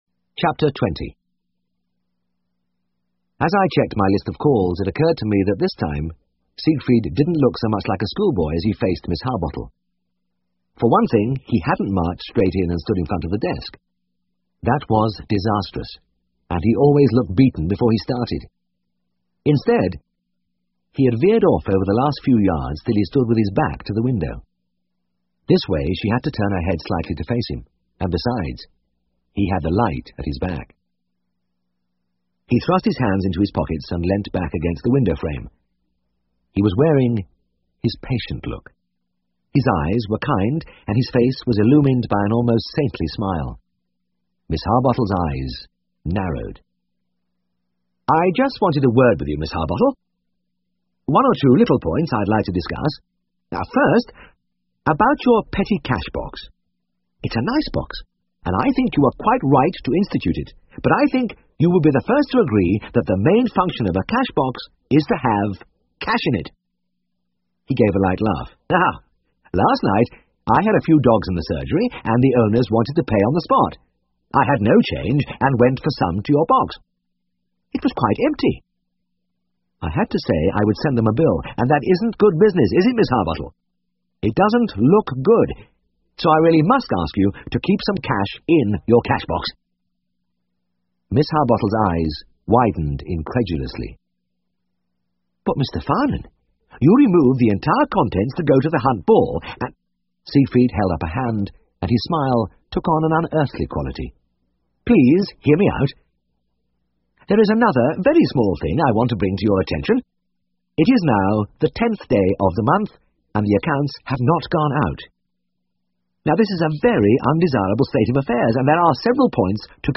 英文广播剧在线听 All Creatures Great and Small 42 听力文件下载—在线英语听力室